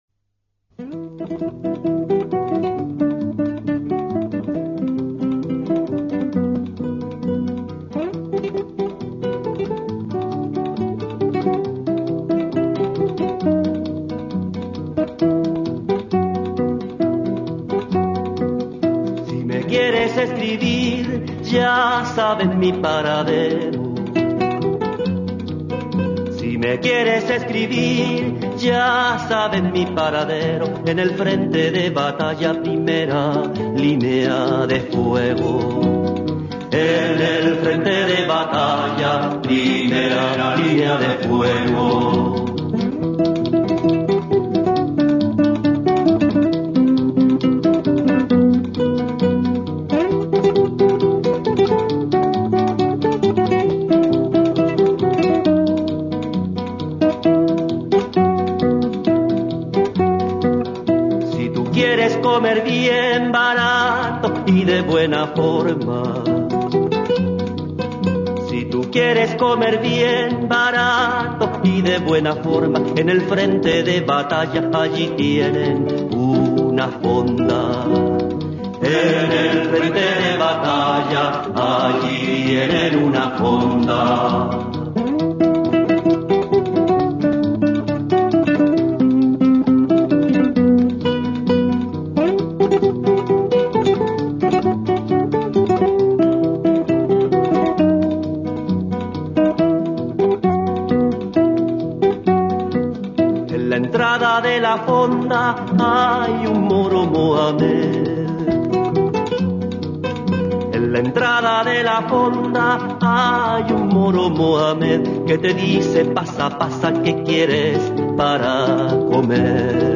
Описание: Испанская песня под гитару